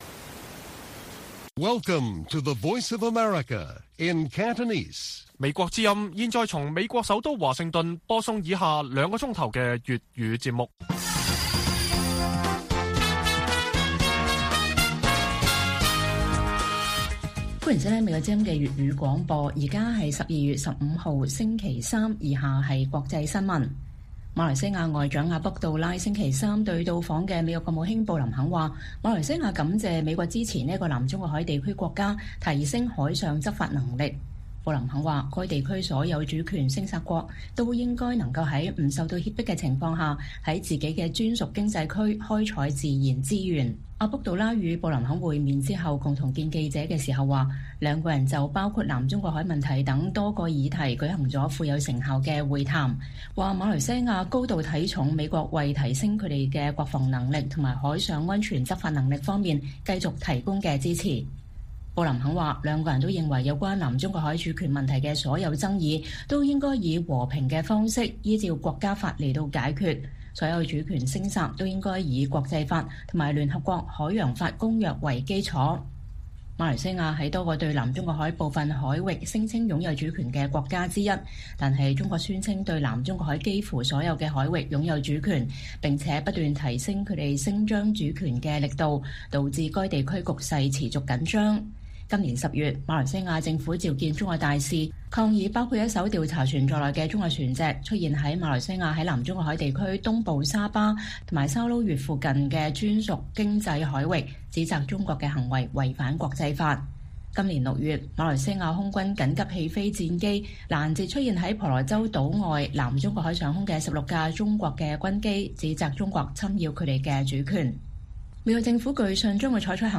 粵語新聞 晚上9-10點 : 香港蘋果日報遭清算港版國安法黑手恐伸入台灣